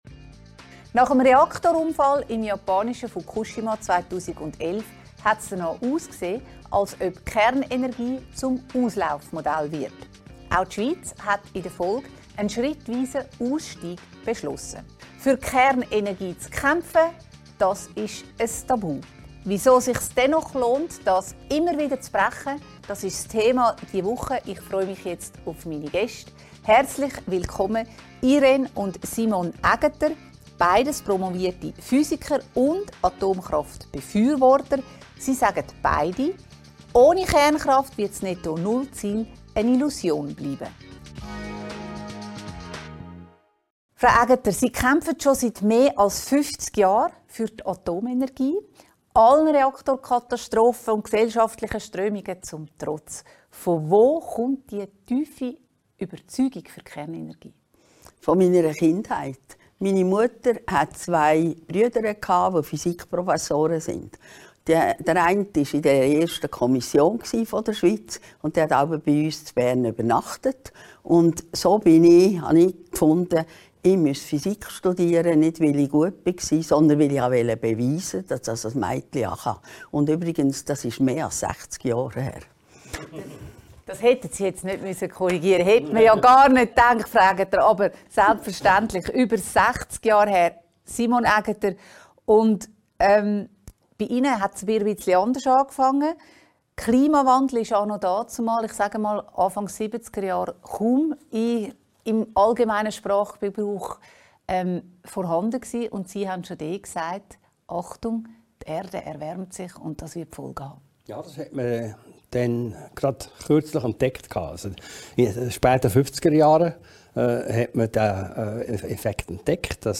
im Gespräch mit den beiden Physikern